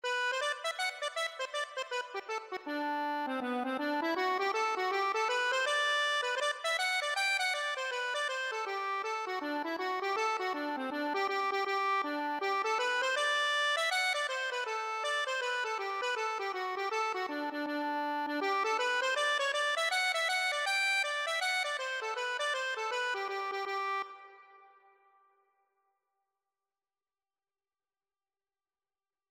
4/4 (View more 4/4 Music)
G major (Sounding Pitch) (View more G major Music for Accordion )
Accordion  (View more Easy Accordion Music)
Traditional (View more Traditional Accordion Music)